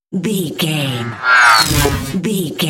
Sci fi appear whoosh
Sound Effects
futuristic
intense
whoosh